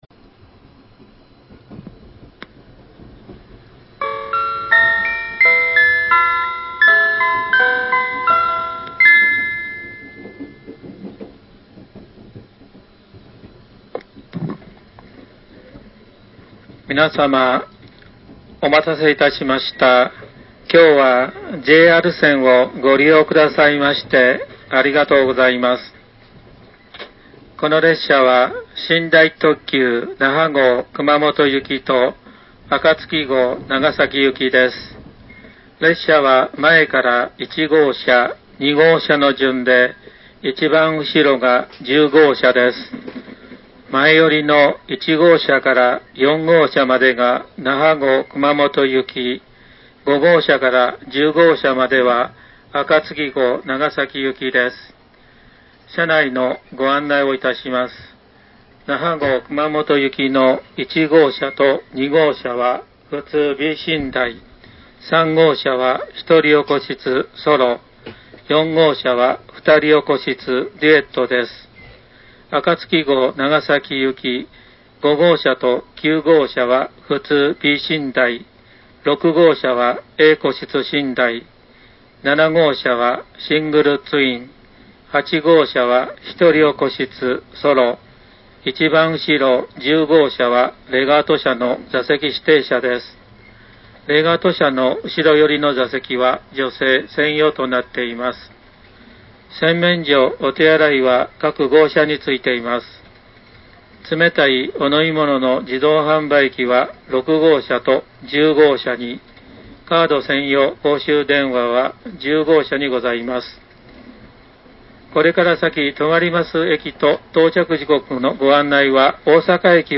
放送については基本的に車内チャイムが鳴った放送を掲載しますが、一部チャイム無しの放送も載せてありますのでご了承ください。
京都到着前   ハイケンスのセレナーデ